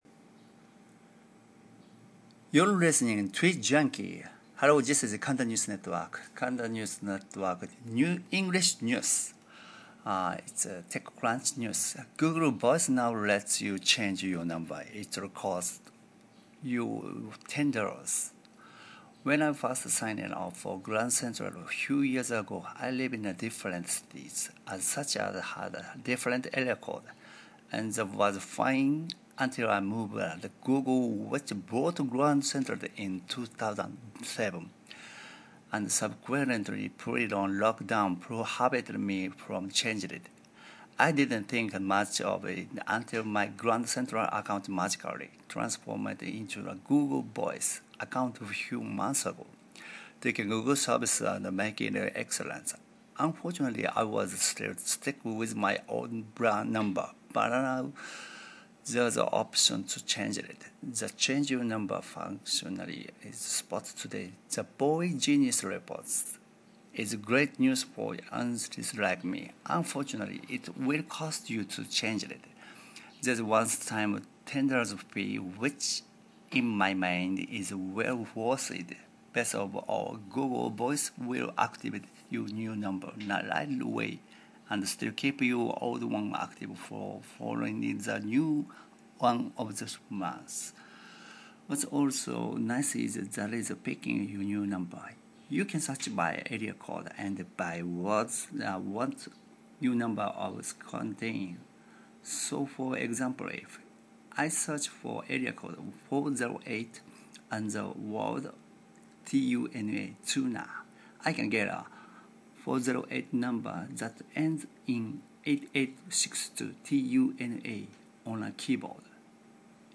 News Reading
35897-news-reading.mp3